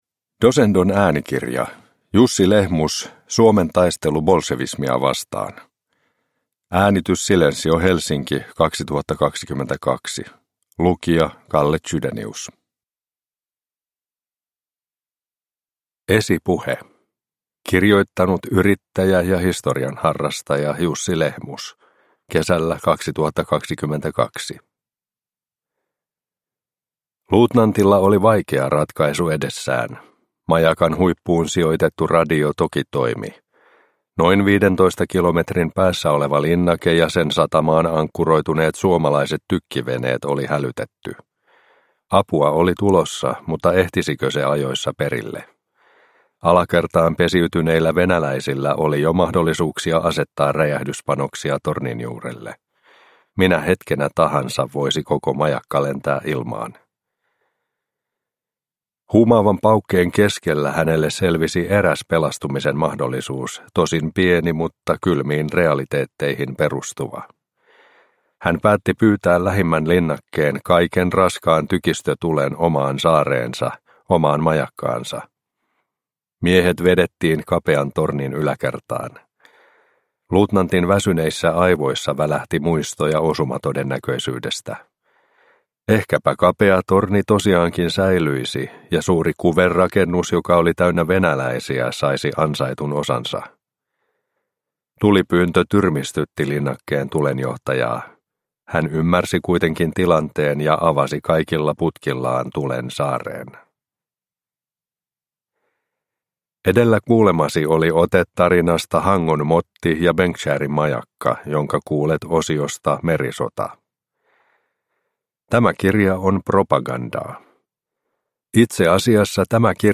Suomen taistelu bolsevismia vastaan – Ljudbok – Laddas ner